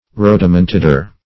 Rhodomontader \Rhod`o*mon*tad"er\, n.